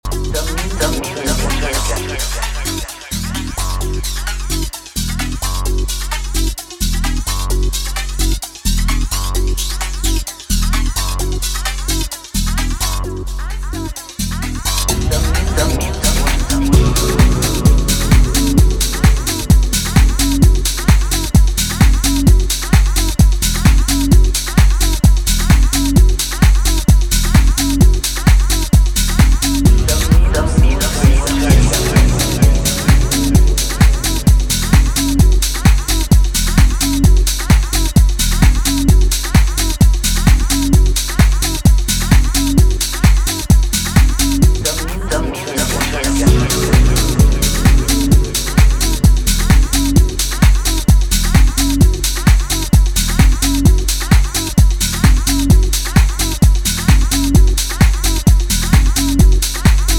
さりげなくエグいFXやローエンドが不明瞭な時間帯に直撃する